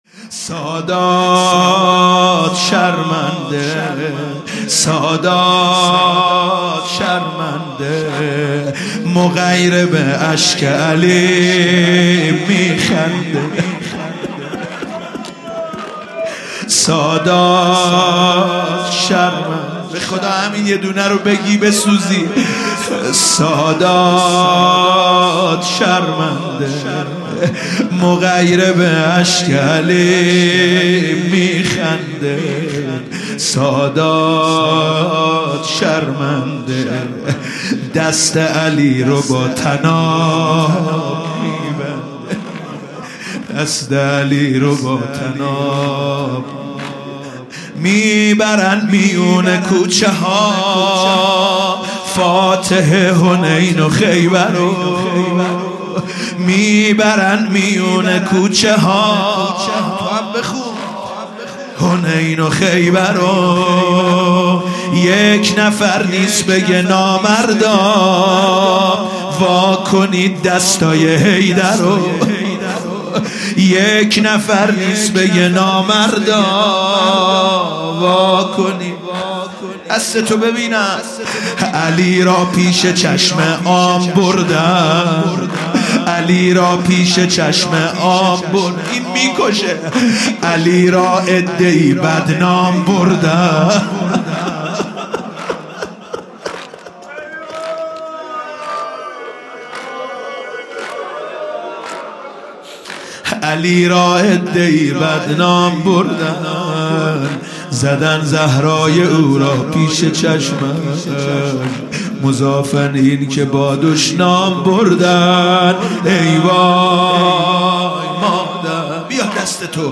مداحی جدید حاج سید مهدی میرداماد 07 بهمن ماه ۱۳۹۸ تهران – هیأت مکتـب الصادق(ع) شب سوم ایام فاطمیه دوم